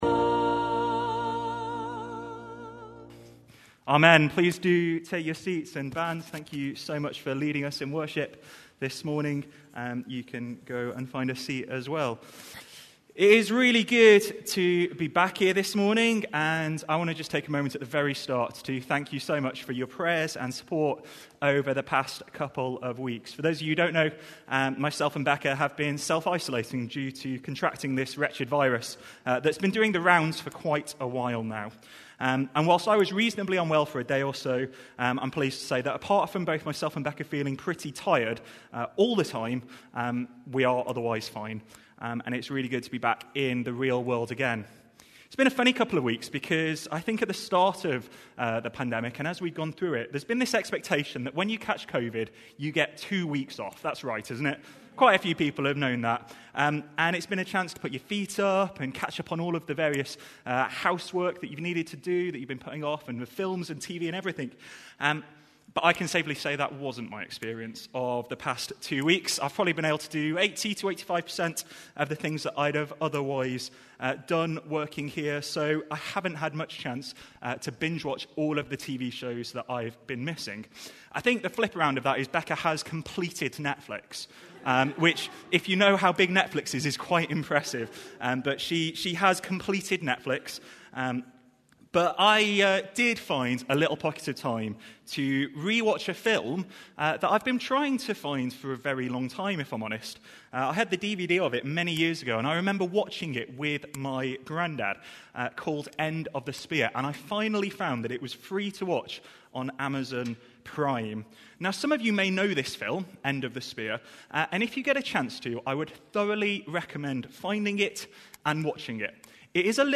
The Gospel of Matthew Watch Listen play pause mute unmute Download MP3 Thanks for joining us as we join together in worship both in-person and online. We're continuing our journey through Matthew's gospel this morning as we explore Jesus' teaching in Matthew 10.